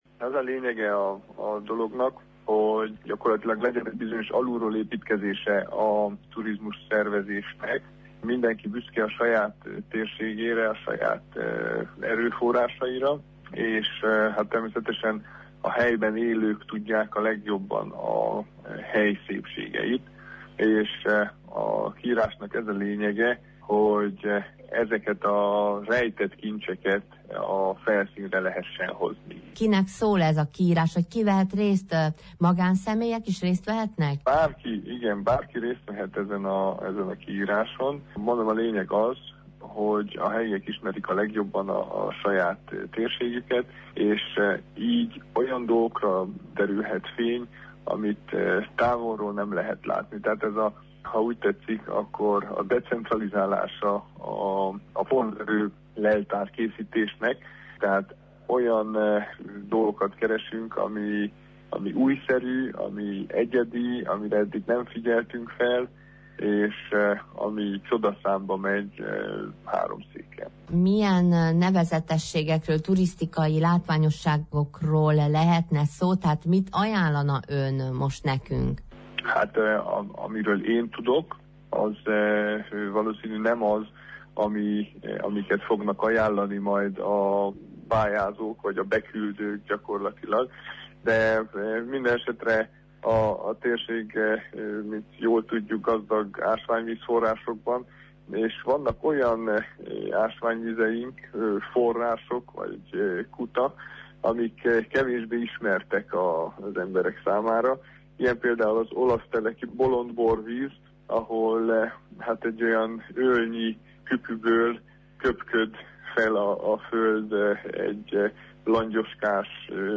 turisztrikai szakember nyilatkozott rádiónknak.